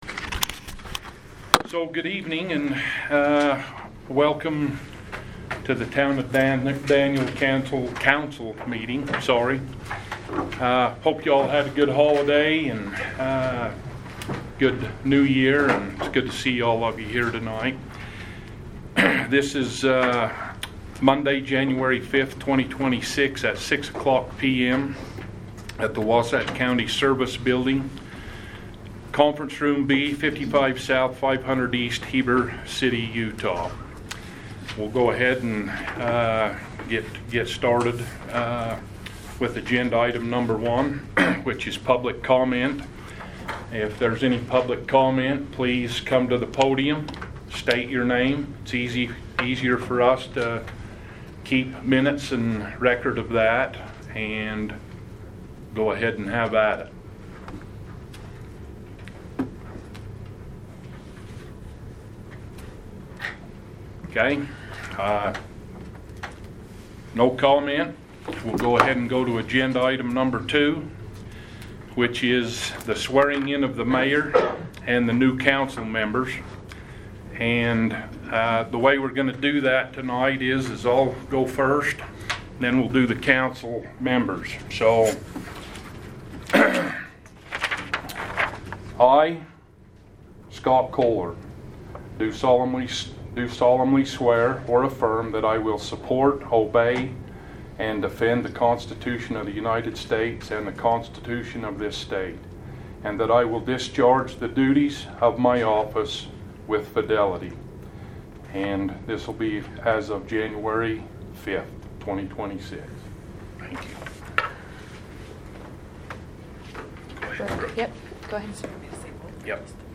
January 5, 2026 Town Council Meeting Audio Minutes